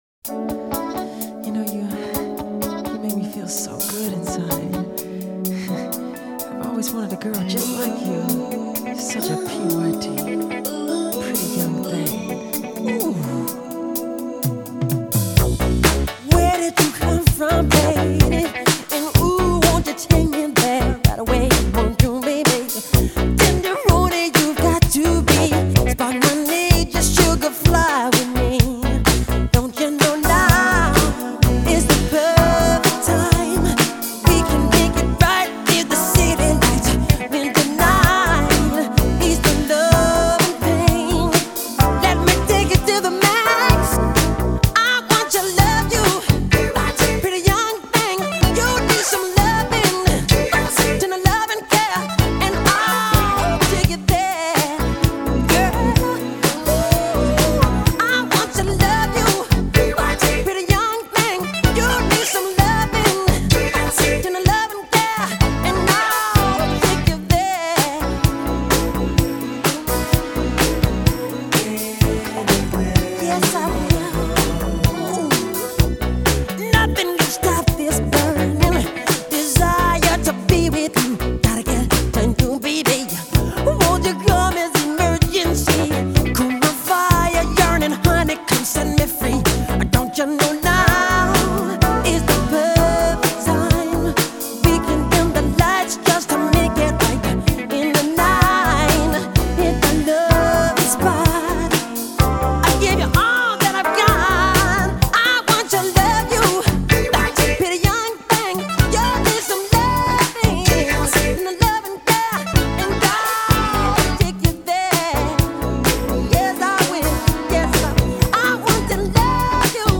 The chipmunk backing vocals in the final few bars?!?